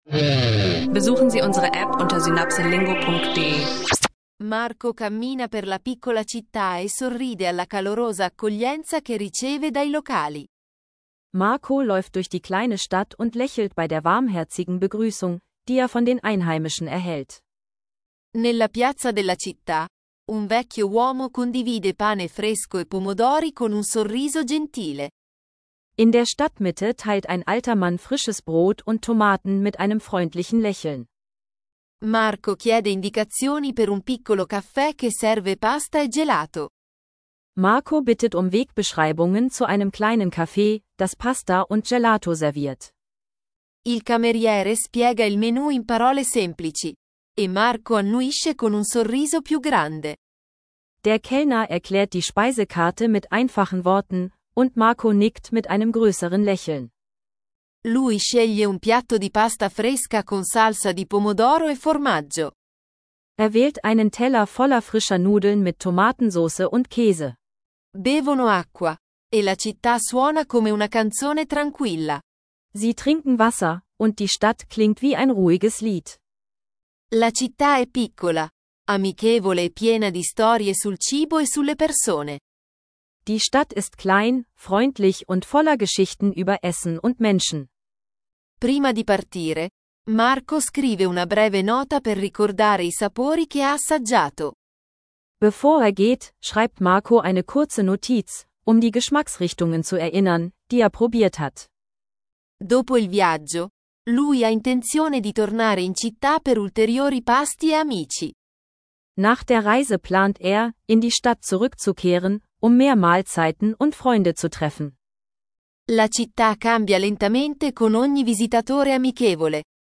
In dieser Folge von Italienisch lernen Podcast tauchen wir ein in Viaggi e Cibo – eine freundliche Einführung in eine kleine italienische Stadt, ihre Küche und die Menschen. Ideal für Italienisch lernen für Anfänger, mit klar verständlichen Dialogen zu alltäglichen Situationen.